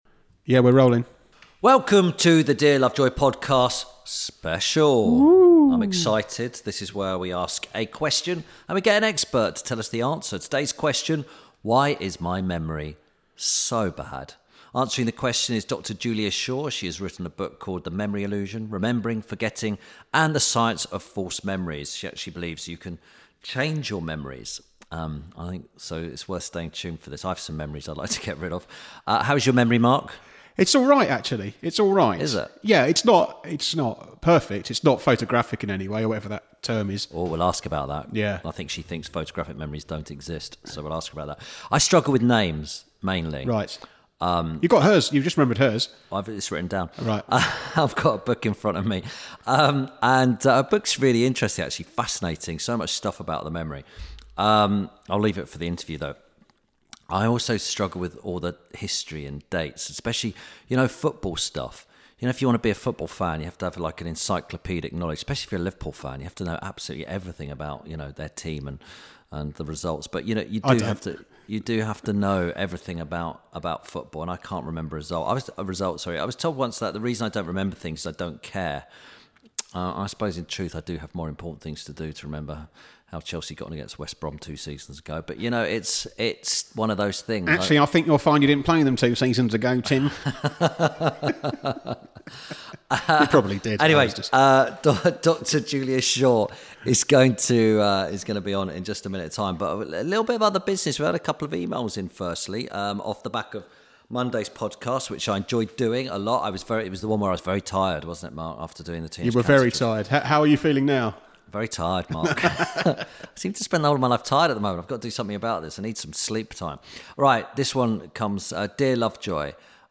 This week Tim Lovejoy talks to Dr Julia Shaw about memory. He remembered to ask about false memories, why memories can’t be trusted sometimes and how we’re able to change our memories.